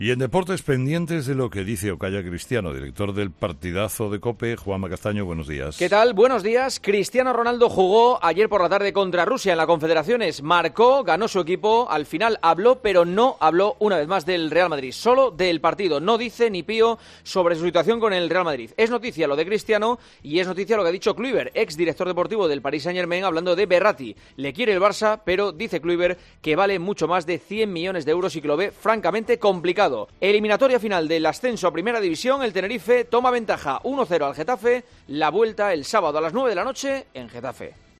AUDIO: El culebrón de Cristiano Ronaldo con Hacienda, en el comentario de Juanma Castaño en 'Herrera en COPE'